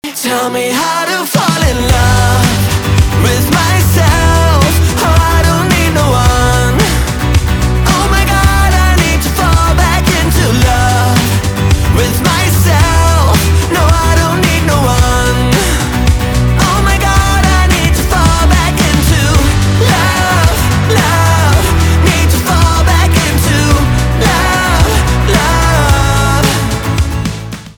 альтернатива
гитара , барабаны
грустные